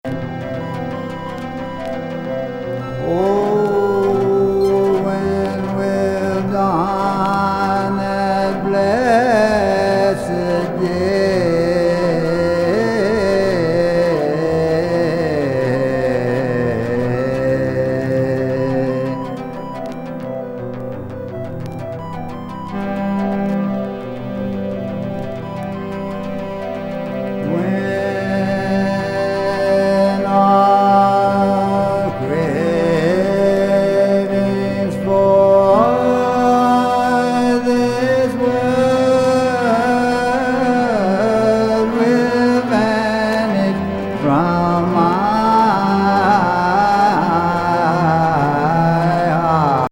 ラーガ+ドローン+宇宙シンセ・ミニマルな82年ライブ。